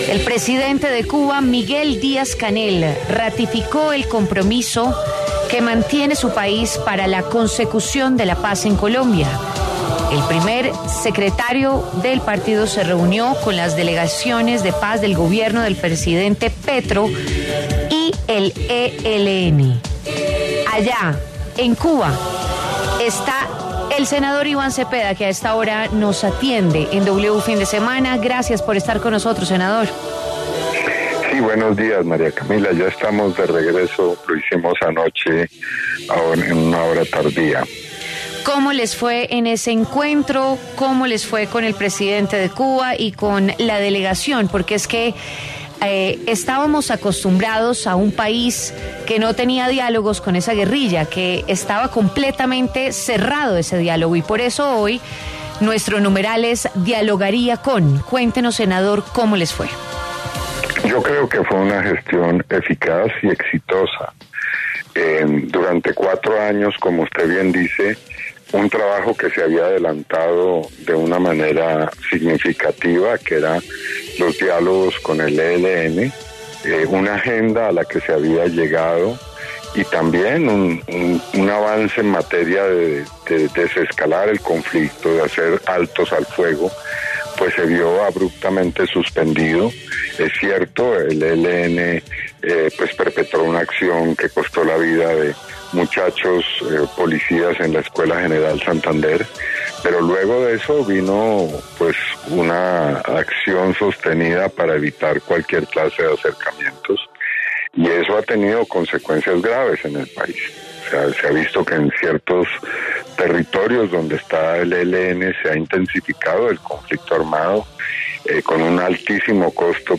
El senador Iván Cepeda, quien viajó a Cuba para el reestablecimiento de diálogos con el ELN, entregó detalles de la reunión con la delegación del grupo armado en W Fin de Semana.